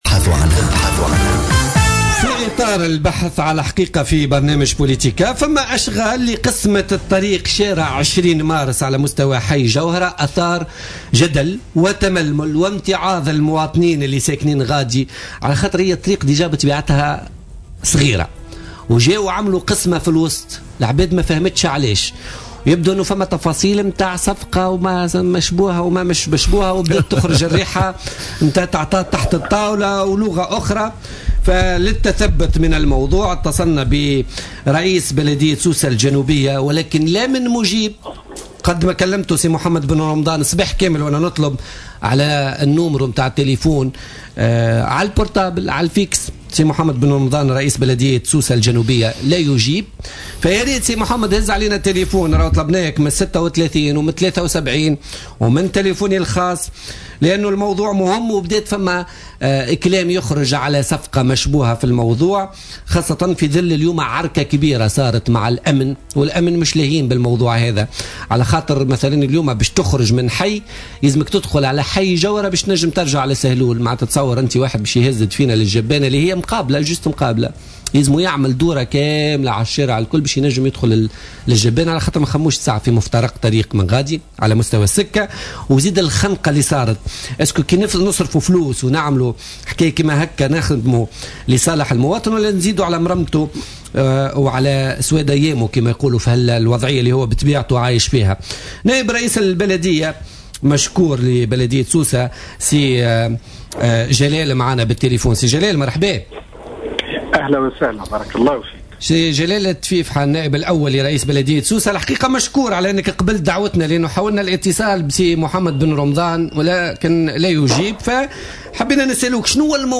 أكد نائب رئيس بلدية سوسة جلال تفيفحة في مداخلة له في بوليتيكا اليوم الخميس 12 ماي 2016 أن مشروع طريق 20 مارس بحي جوهرة مشروع فاشل قبل أن يبدأ موضحا أنه قال هذا الكلام لرئيس البلدية خلال مجلس بلدي.